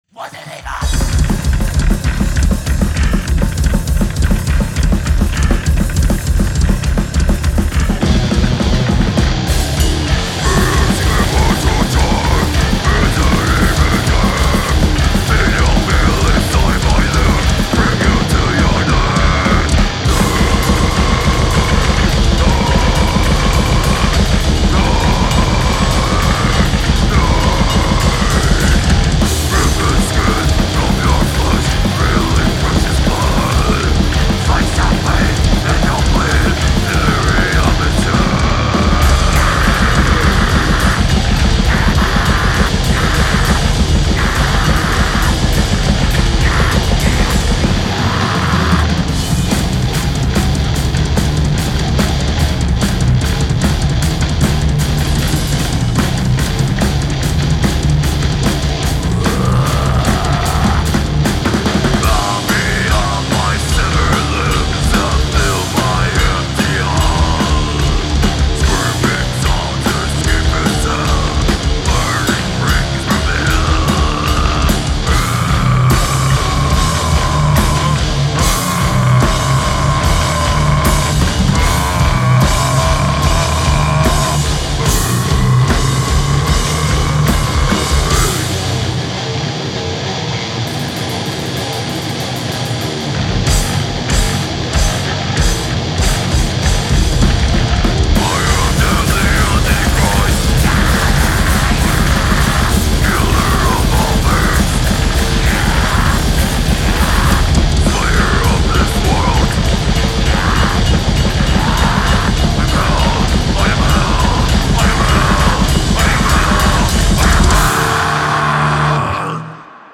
death metal/grindcore